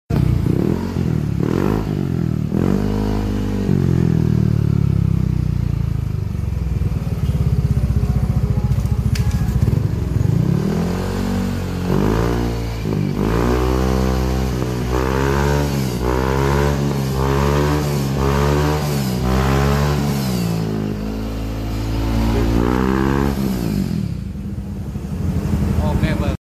Cek sound knalpot standar racing sound effects free download
Cek sound knalpot standar racing sunblue series nmax old